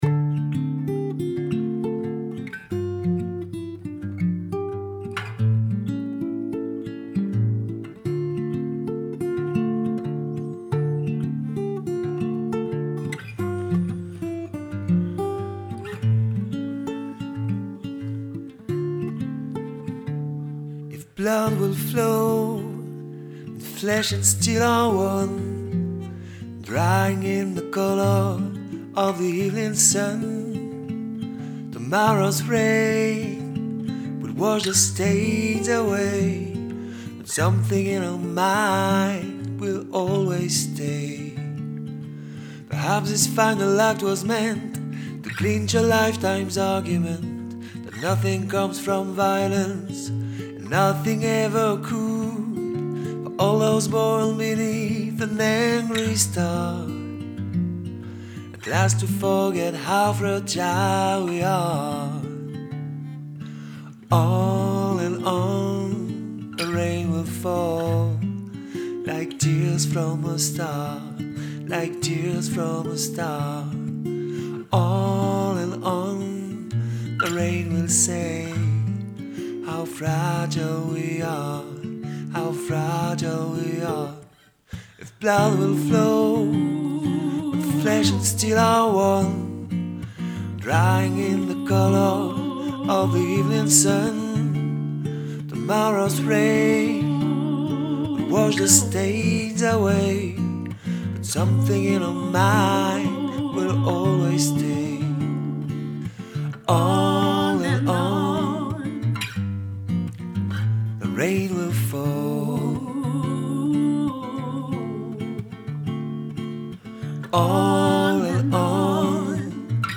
chant chorale Party!
La piste ténor
Fragile-Tenor.mp3